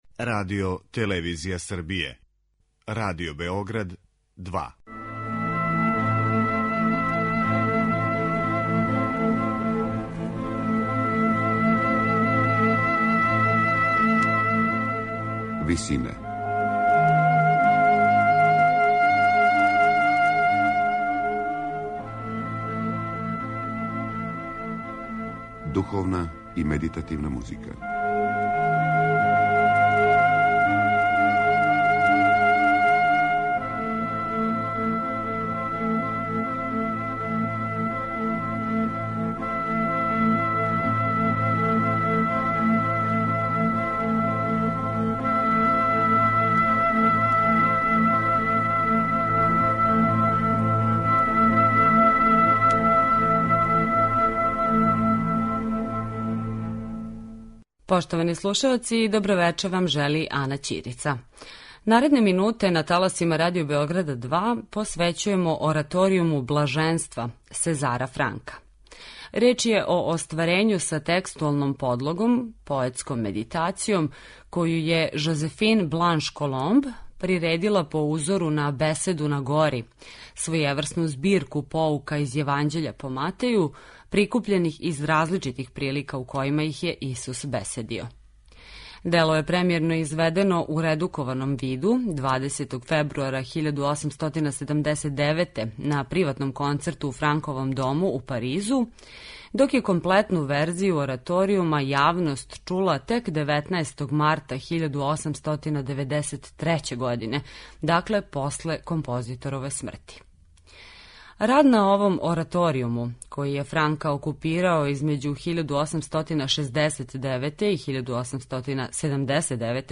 Сезар Франк: ораторијум „Блаженства"
у ВИСИНАМА представљамо медитативне и духовне композиције аутора свих конфесија и епоха.